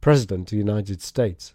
And in very rapid speech, the weak consonant /ð/ can be lost and the vowels merged into one:
There, as in jack o’lantern and three o’clock, the only thing between President and United States is a little schwa.